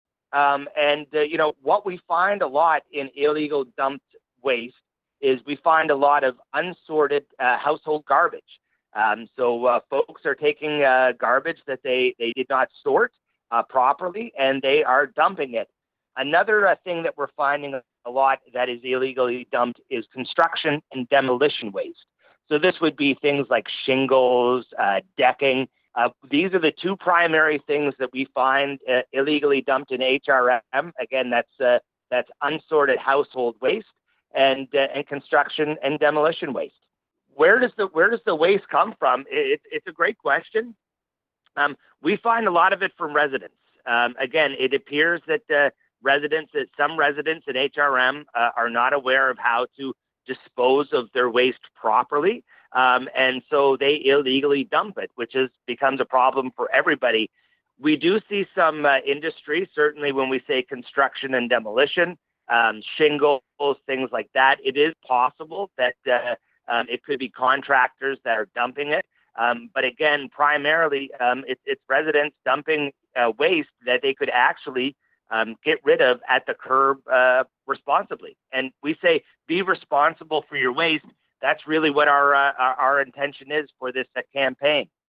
nous explique ce qu’il en est à l’occasion de l’entretien qu’il a accordé à OUI 98,5 FM